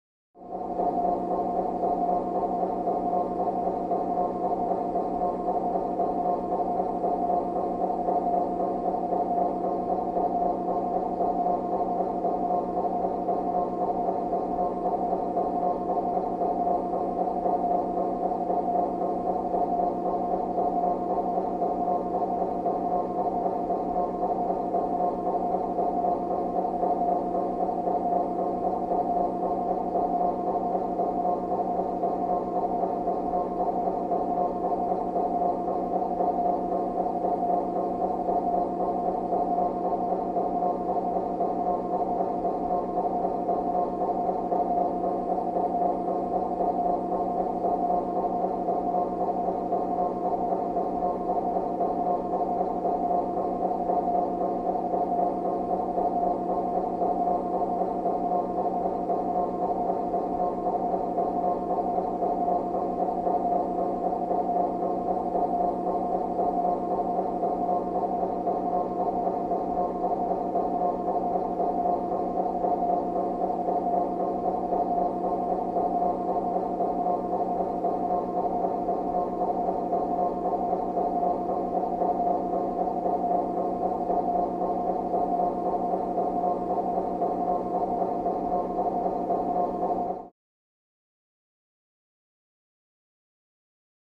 Meteor Room Fan | Sneak On The Lot